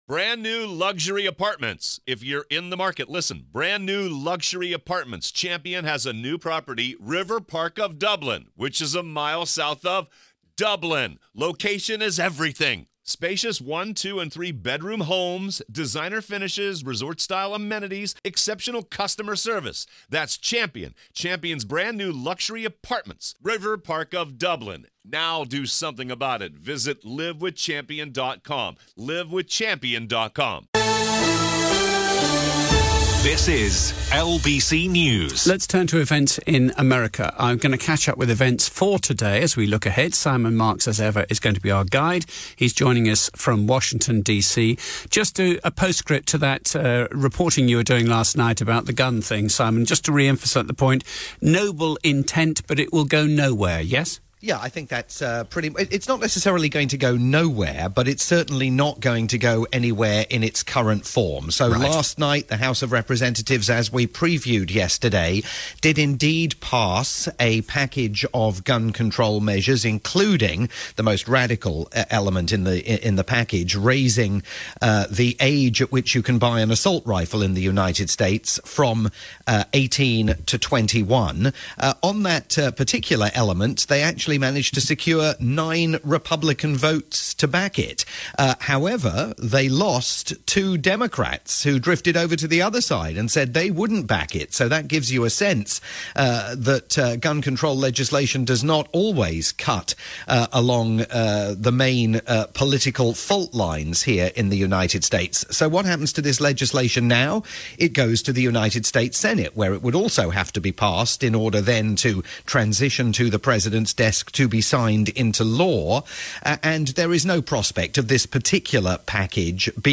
live Thursday roundup for LBC News' lunchtime sequence